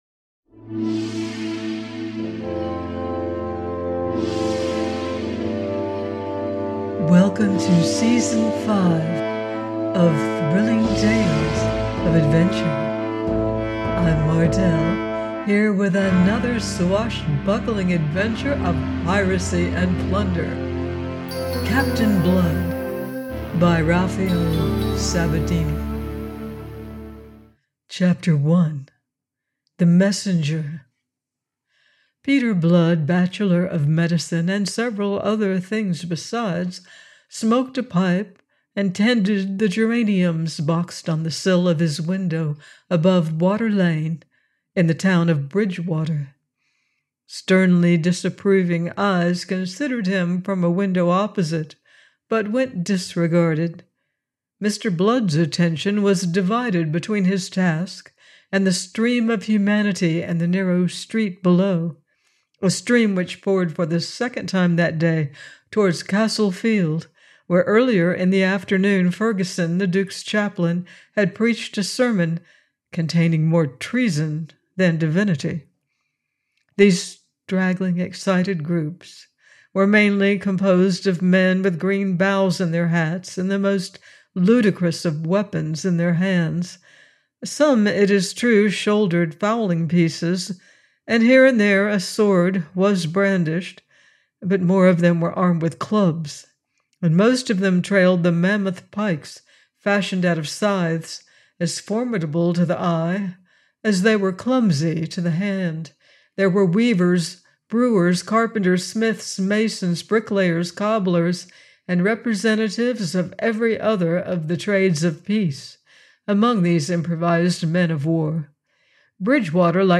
Thrilling Tales Of Adventure - audiobooks 2 delight all ages